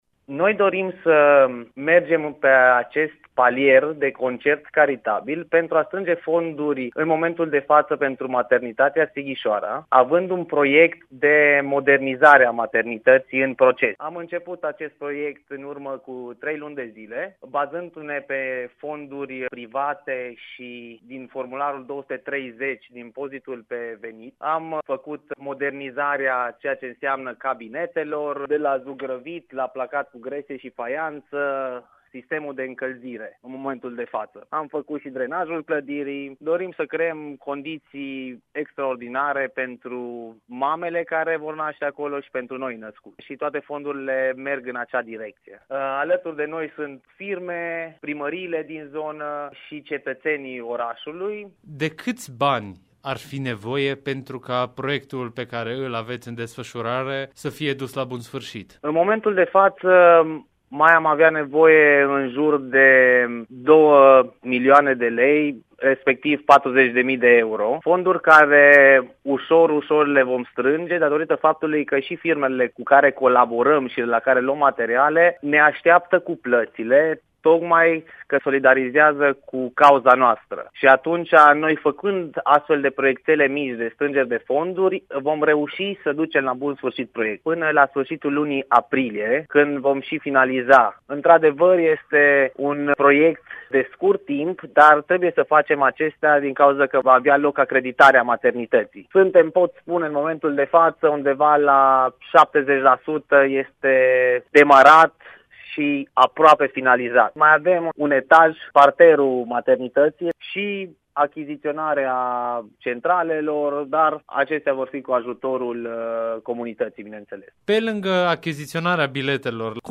interviu-concert-caritabil-sighisoara.mp3